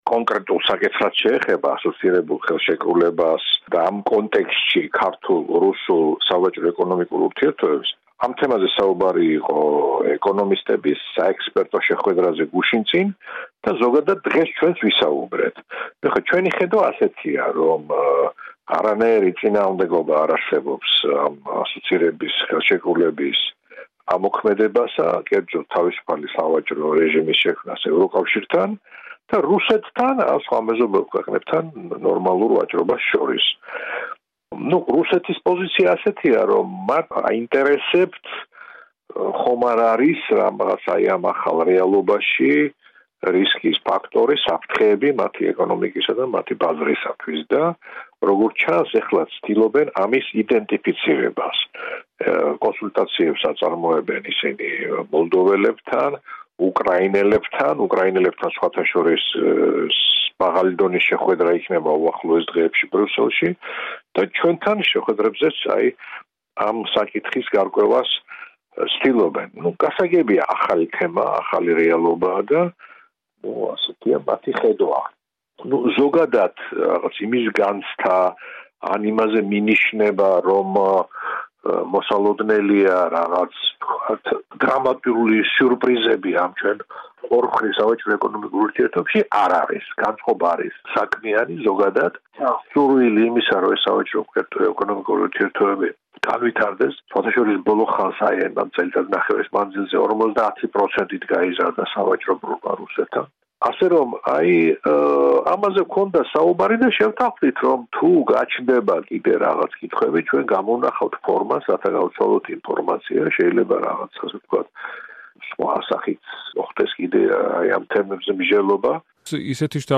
საუბარი ზურაბ აბაშიძესთან